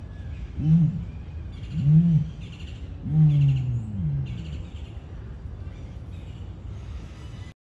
Vocalización de uña avestruz macho sound effects free download